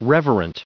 655_reverent.ogg